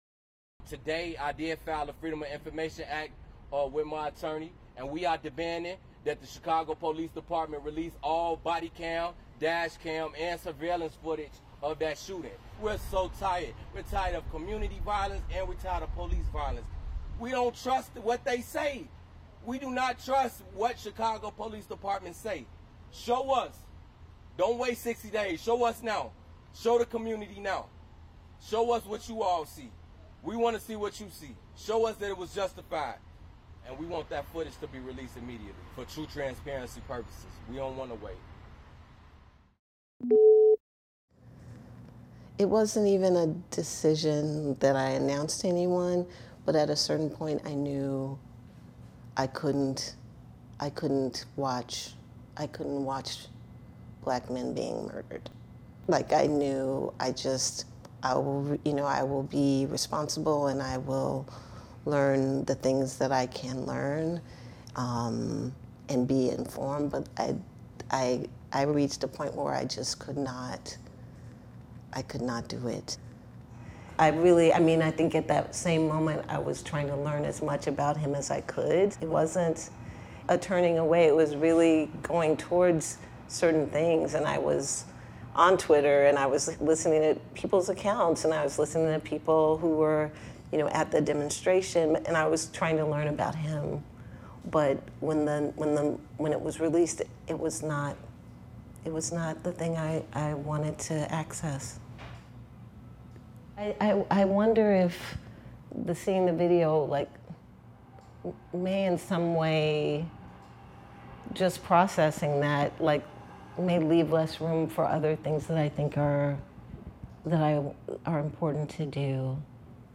held a press conference outside CPD headquarters and demanded that the police release all video footage of the shooting.